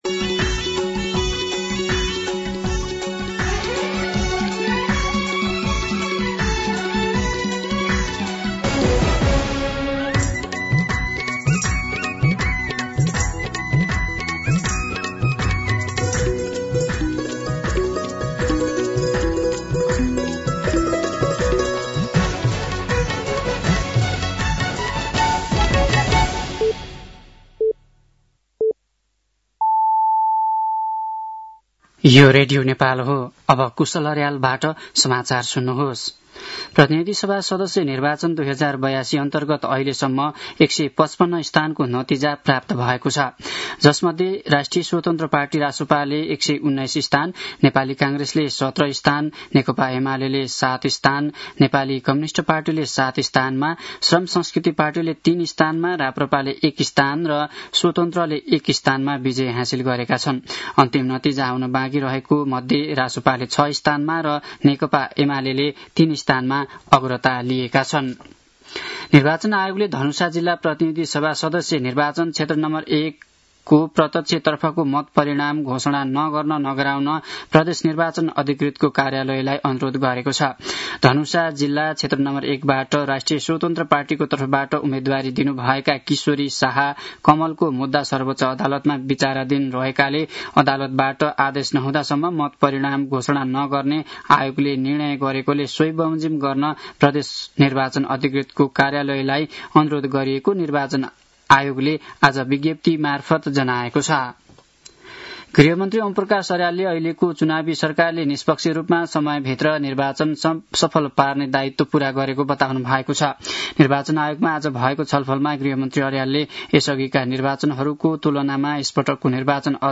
साँझ ५ बजेको नेपाली समाचार : २४ फागुन , २०८२
5.-pm-nepali-news-.mp3